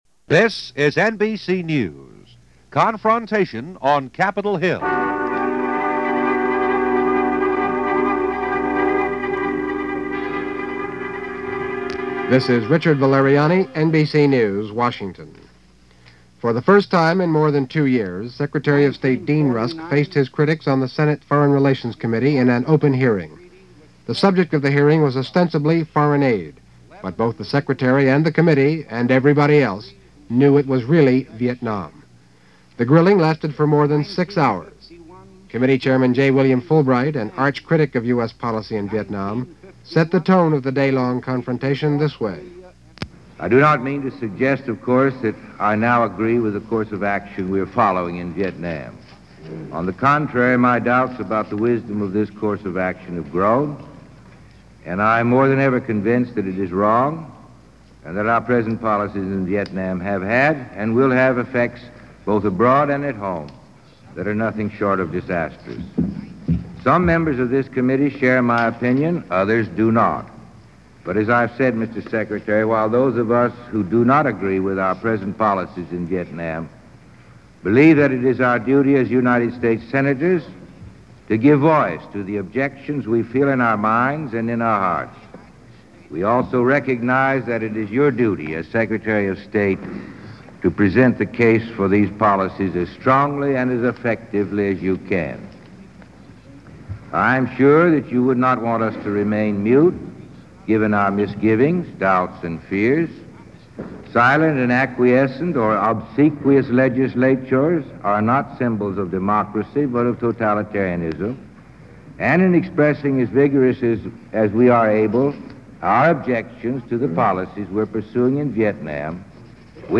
March 11, 1966 - Confrontation On Capitol Hill - Senate Foreign Relations Committee Hearings - First day of hearings on the Vietnam War.
When Secretary of State Dean Rusk went to Capitol Hill to testify before the Senate Foreign Relations Committee he was confronted, almost from the get-go by ranking members including Senator J. William Fulbright, over just what the plan was for Southeast Asia.
Here are highlights of the days testimony as presented by NBC Radio on March 11, 1966.